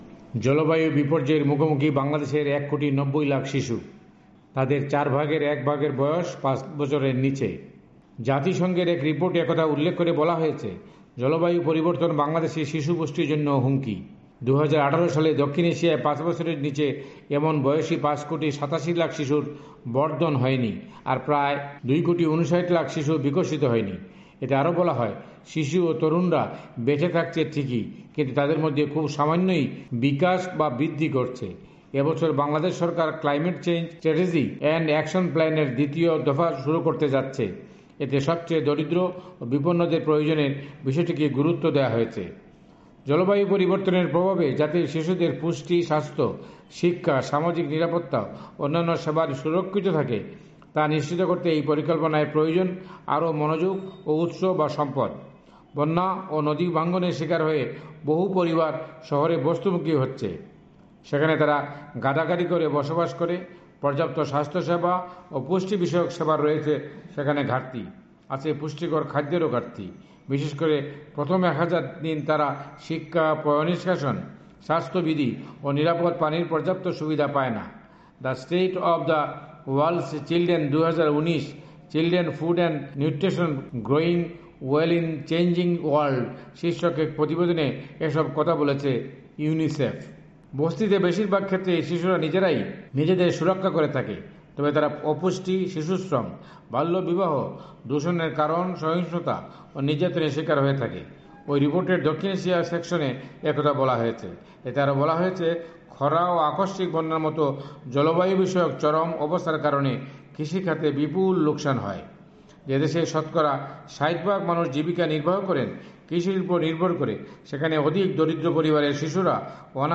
এ সম্পর্কে বিস্তারিত জানিয়েছেন ঢাকা থেকে